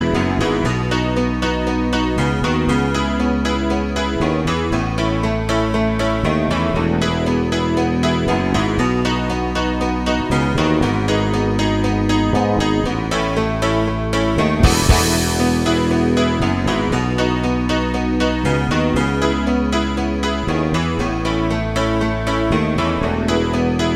No Guitars Or Backing Vocals Rock 4:26 Buy £1.50